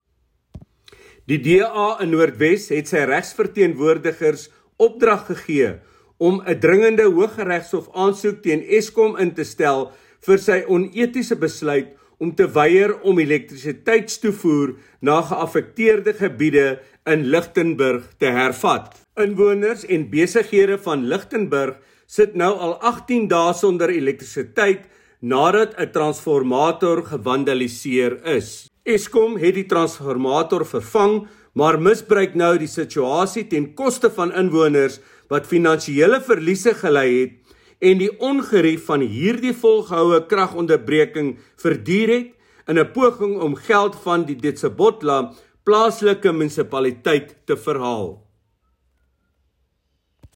Note to Broadcasters: Please find linked soundbites in
Afrikaans by Leon Basson MP
DA-legal-action-Eskom-Leon-Basson-AFR.mp3